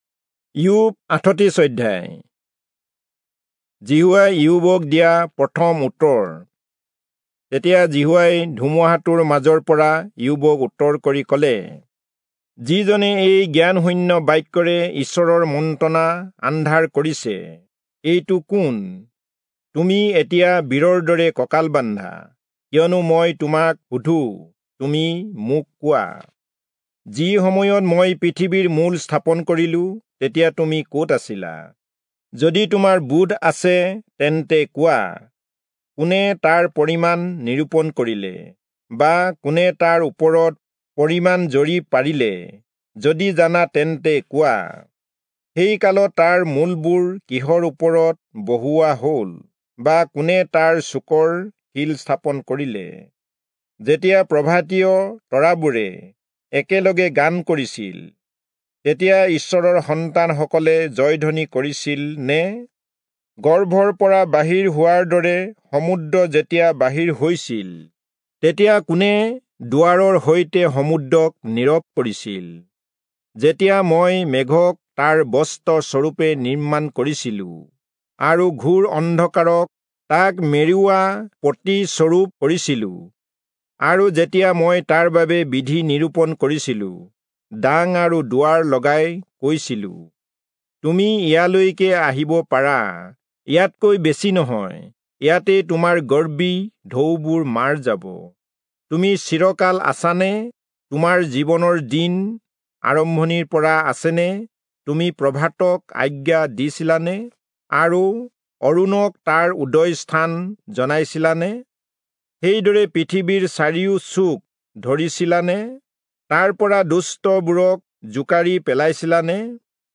Assamese Audio Bible - Job 25 in Tev bible version